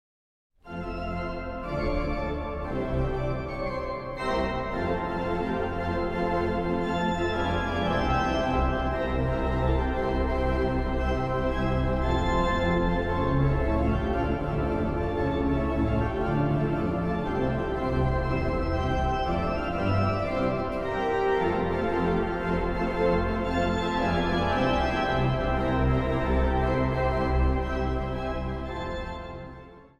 Instrumentaal | Orgel